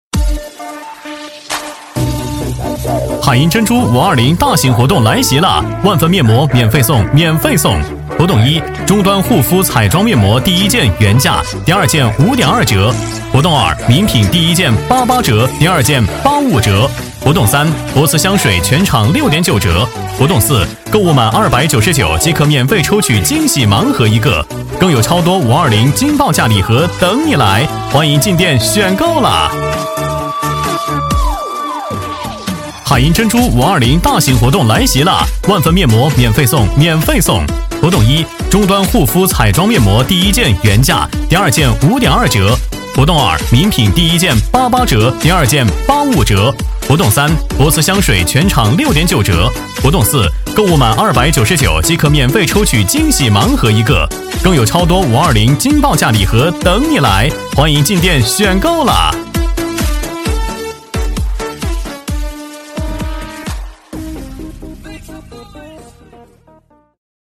【男19号促销】海莹珍珠
【男19号促销】海莹珍珠.mp3